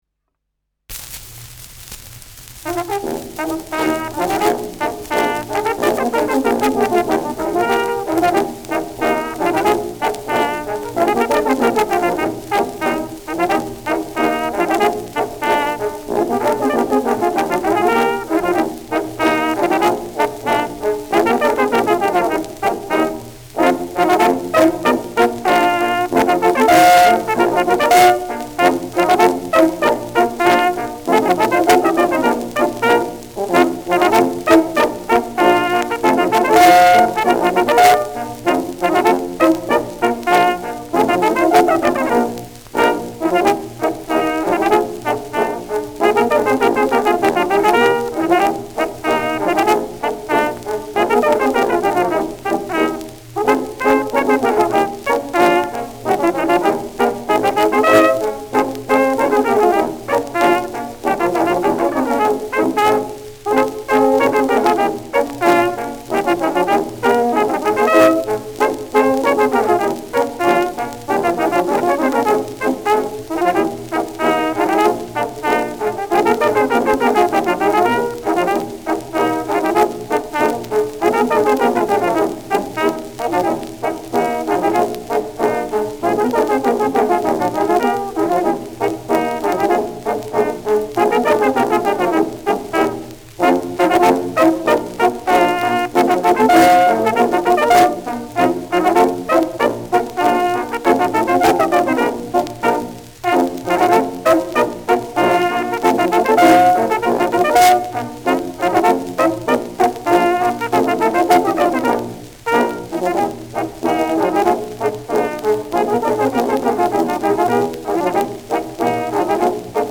Schellackplatte
präsentes Rauschen : leichtes Knistern : Nadelgeräusch gegen Ende : „Schnarren“ bei großer Lautstärke
Innviertler Bauernkapelle Solinger (Interpretation)